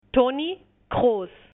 NOMBREPRONUNCIACIÓNHABLANTE NATIVOHISPANOHABLANTE
Toni KROOSTóni Kros